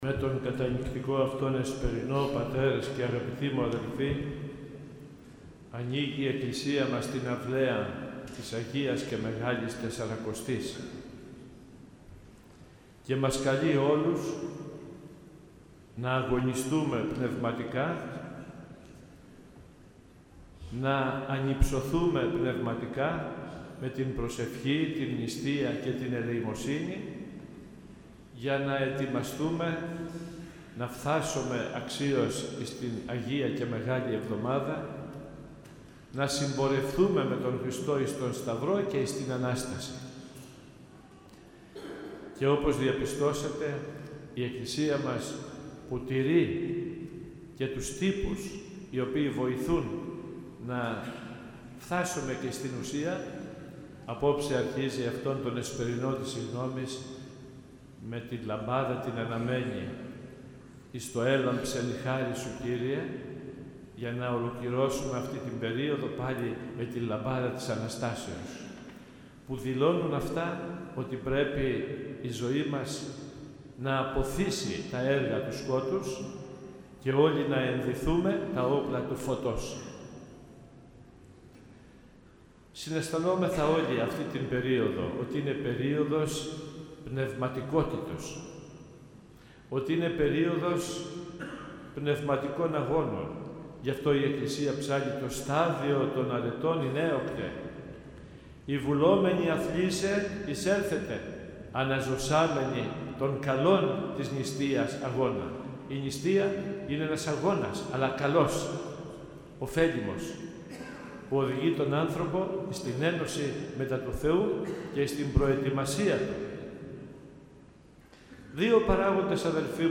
Με την πρέπουσα Εκκλησιαστική τάξη και μέσα σε κλίμα ιερής κατάνυξης, όπως κάθε χρόνο έτσι και φέτος, τελέσθηκε ο Εσπερινός της Συγγνώμης την Κυριακή της Τυρινής 18 Φεβρουαρίου ε.ε., εις τον Ιερό Μητροπολιτικό Ναό, χοροστατούντος του Σεβασμιωτάτου Μητροπολίτου μας κ. Νικολάου.
omilia_sevasmiotatoy_esperinos_syggnomis_2018.mp3